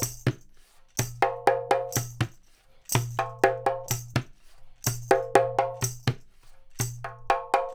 124-PERC7.wav